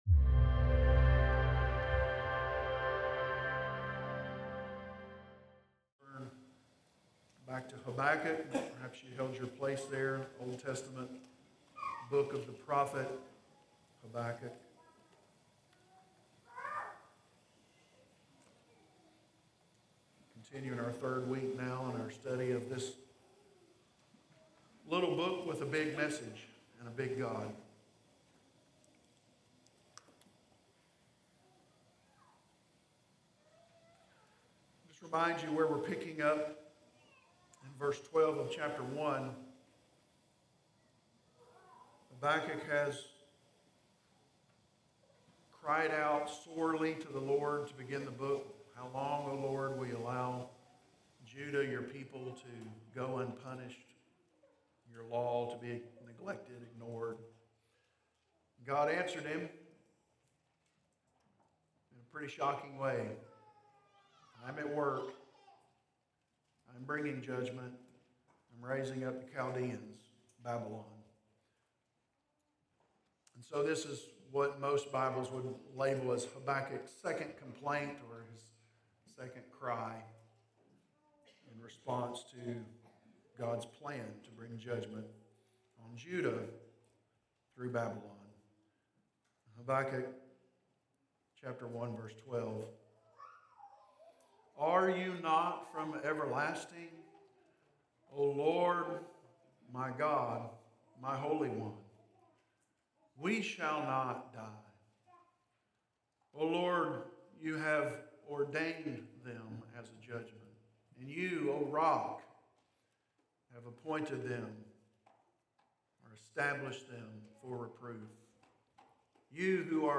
Sermons recorded during the Sunday morning service at Corydon Baptist Church in Corydon, Indiana